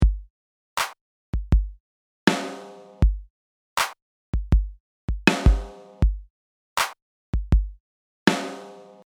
In the following example I’ve used a simple electronic beat and added a snare hit on a separate track so I can process it independently.
Here are the two elements together, drier than the Sahara desert.
Pretty boring, but it’ll do.